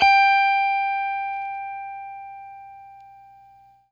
FENDERSFT AQ.wav